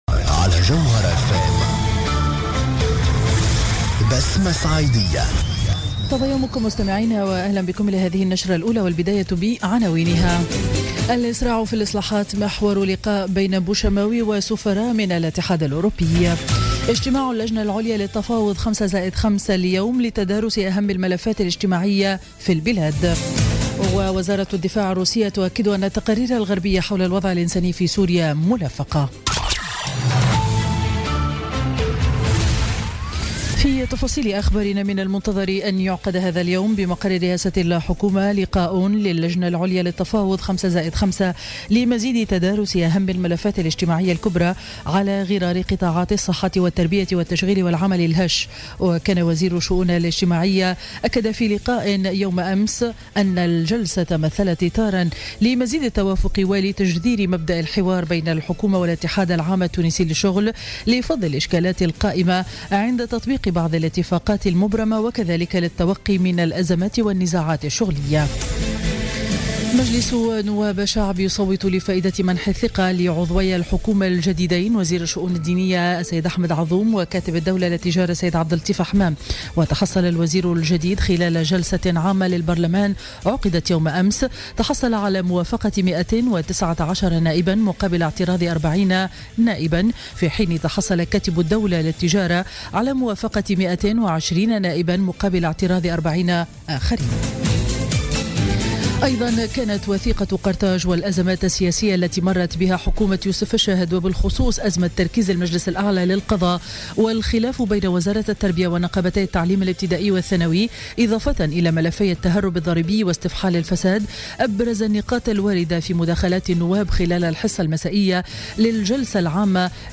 نشرة أخبار السابعة صباحا ليوم الجمعة 17 مارس 2017